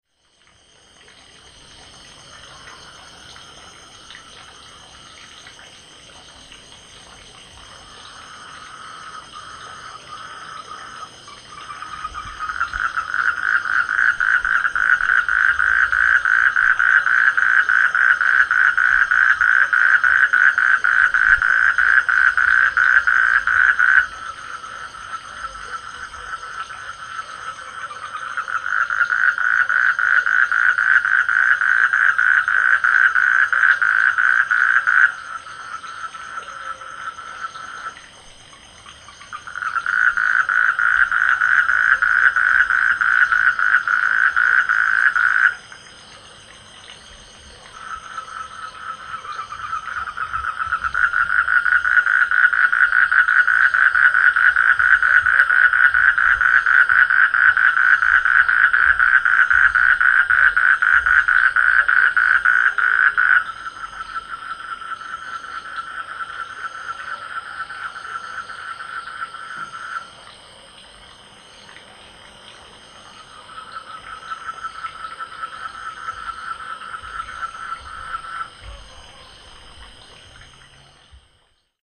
field recordings
the rice fields of Penestanan Villiage